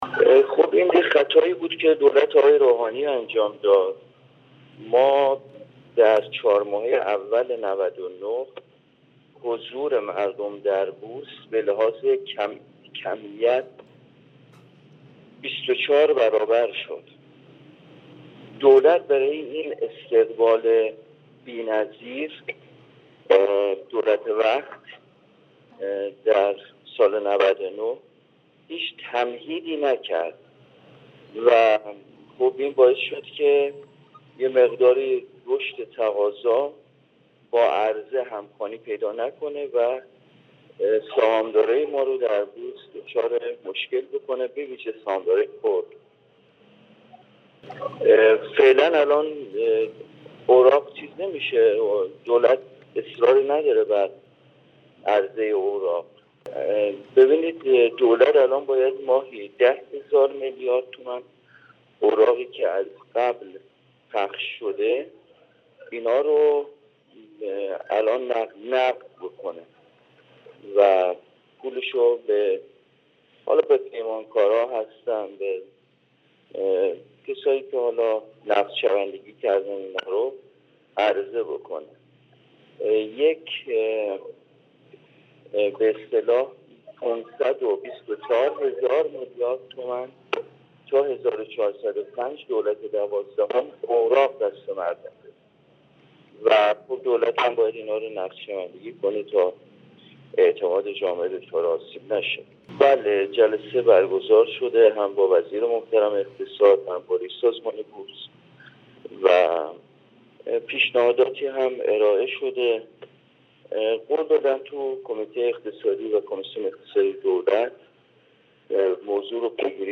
سید محمدرضا میر تاج الدینی، نایب رئیس کمیسیون برنامه و بودجه مجلس شورای اسلامی در گفت‌وگو با بورس نیوز، درباره وضعیت بازار اظهارکرد: دولت باید تمرکز خود را به سمت سهامداران خرد سوق دهد چرا که بیشترین ضرر و زیان در دولت قبلی به آن‌ها وارد شده است.